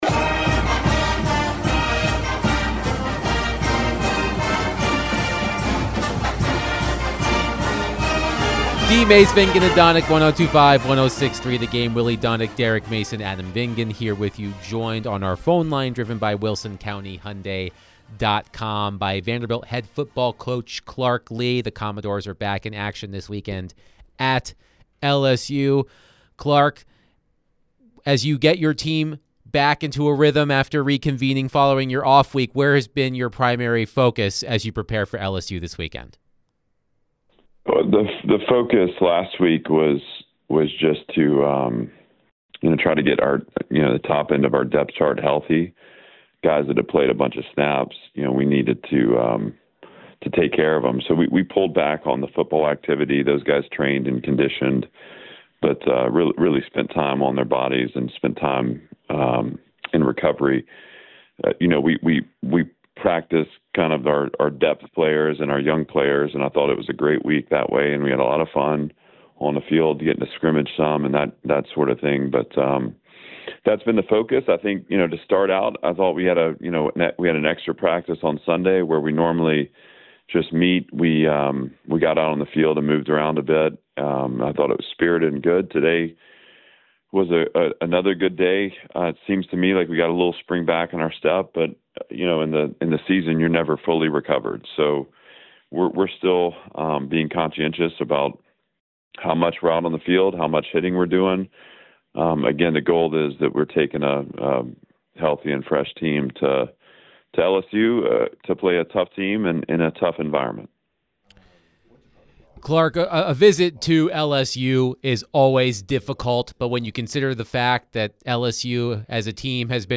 Vanderbilt head football coach Clark Lea joined the show to discuss his team's matchup with LSU this weekend. What will the Commodores have to do in order to come away with a win in Baton Rouge on Saturday?